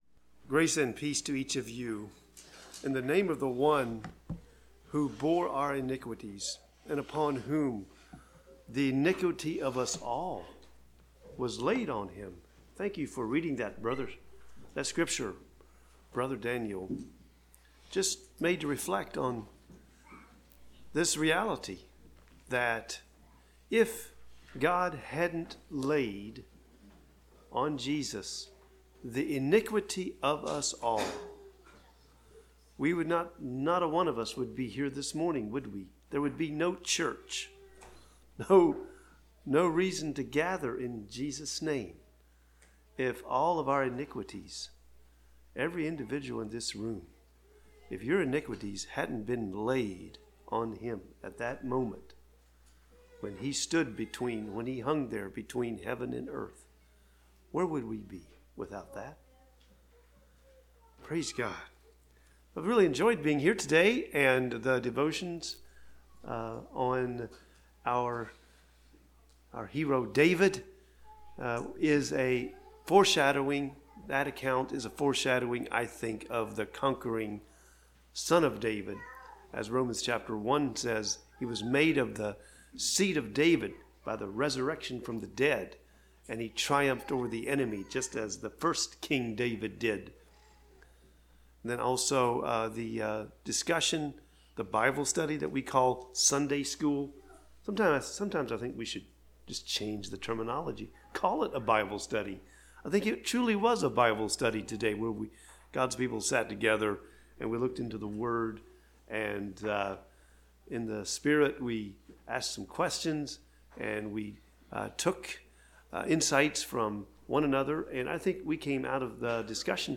Service Type: Spring Conference Meetings Topics: Spiritual Israelite « I Am a Priest